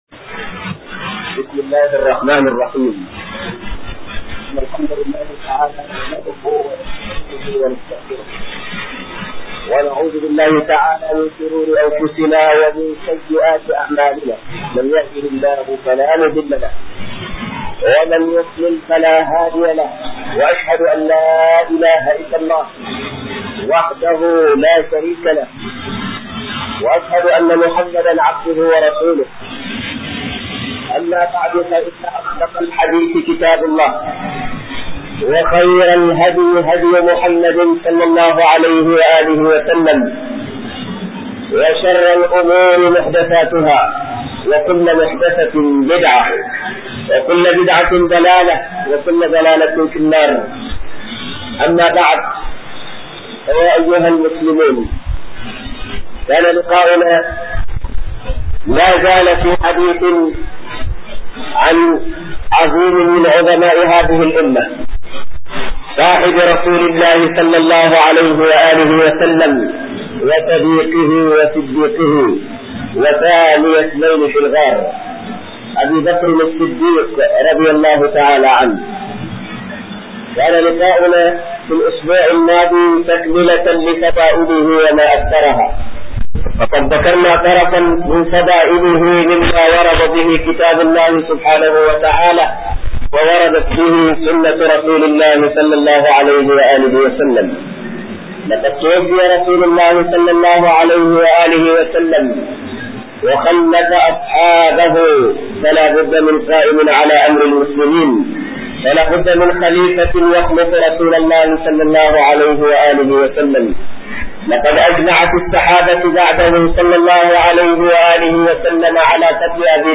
(19) KHUDBA.mp3